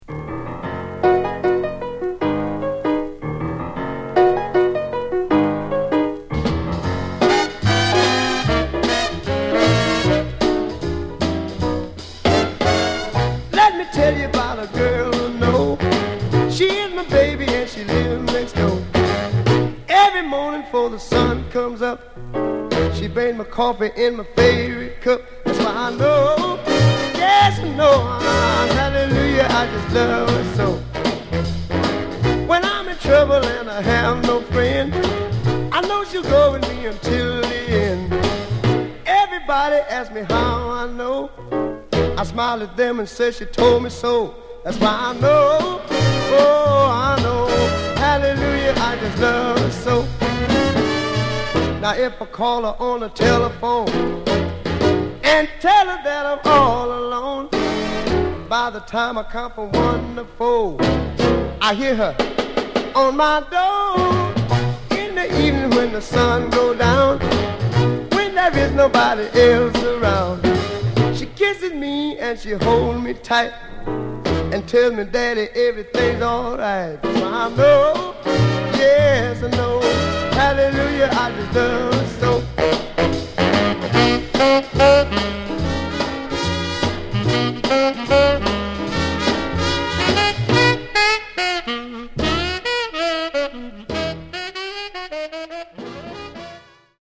Genre: Rockin' RnB